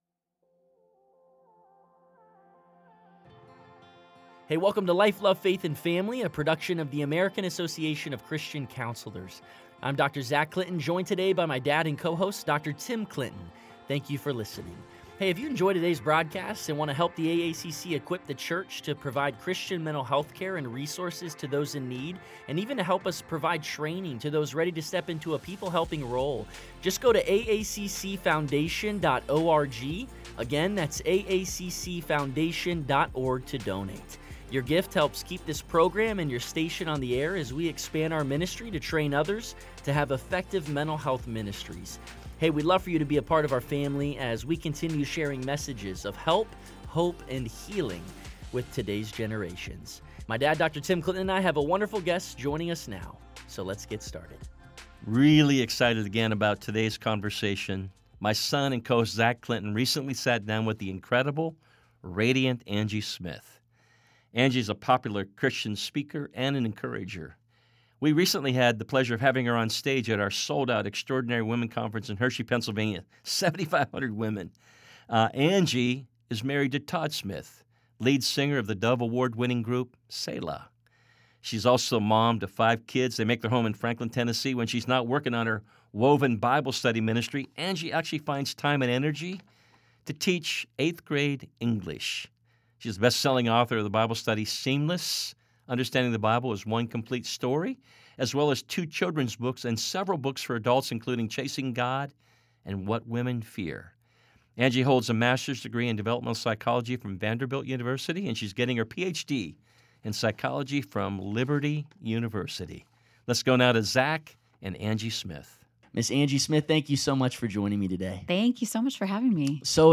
a joyful conversation